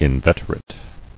[in·vet·er·ate]